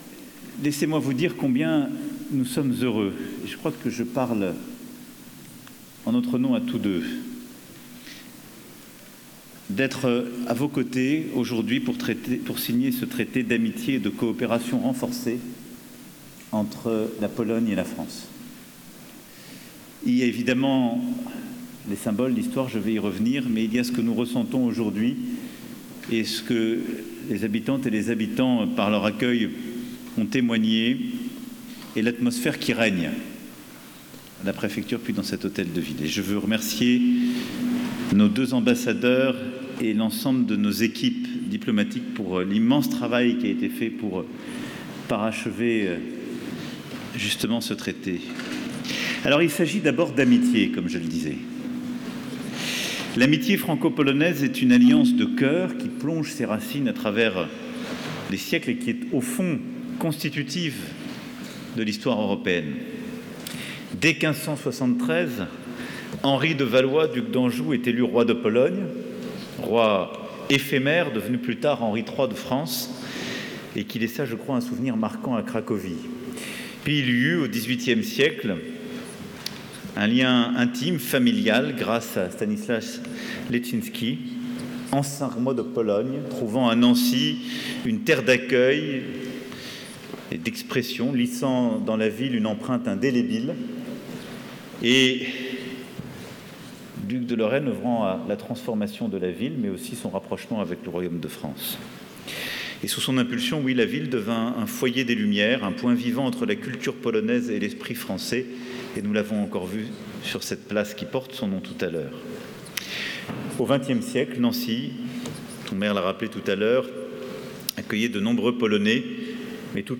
Le Président de la République, Emmanuel Macron, s’est rendu à Nancy, vendredi 9 mai 2025, à l’occasion de la signature du traité d’amitié franco-polonais.